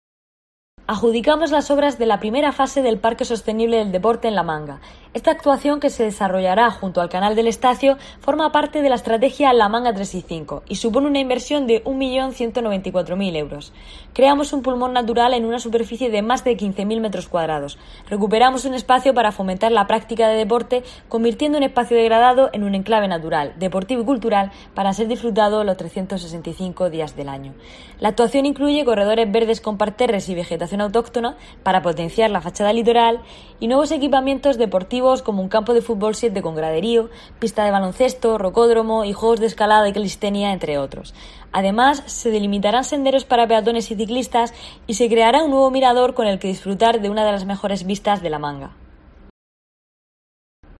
Marina Munuera, directora general de Movilidad y Litoral